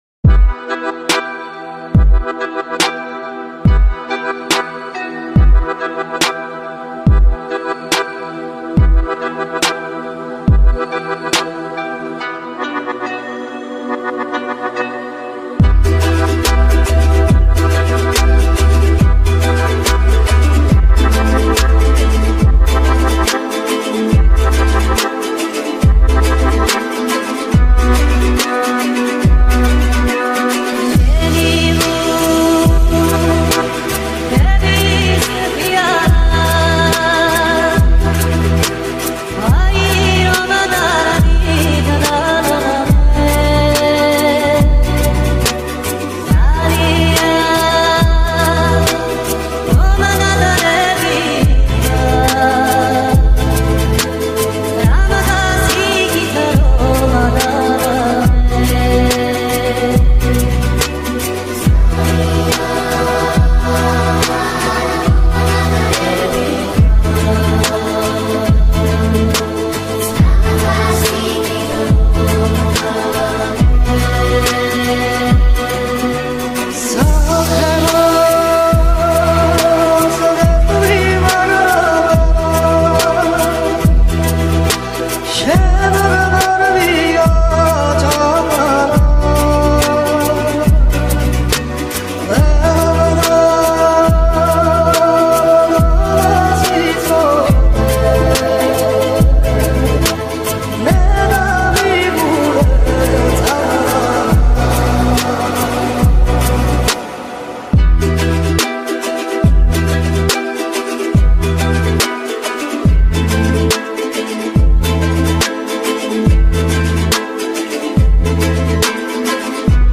Gruzinskaya_Trap_Geian_Trap.mp3